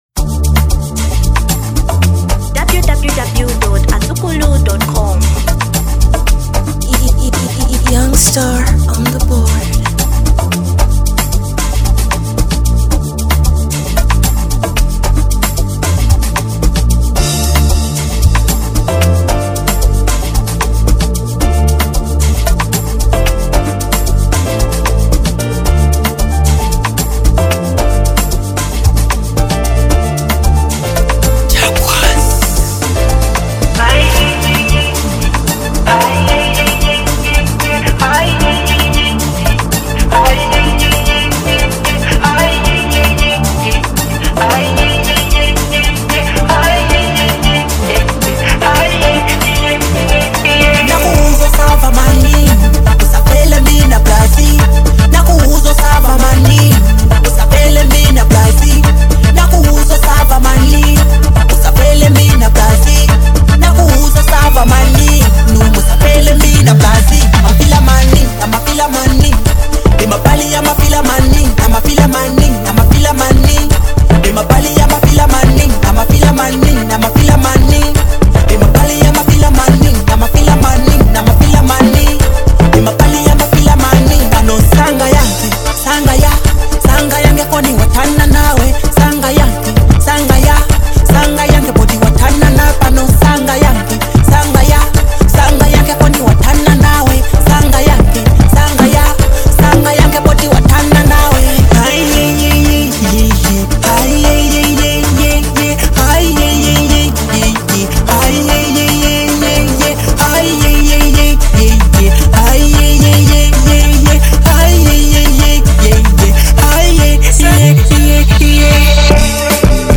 Genre Amapiano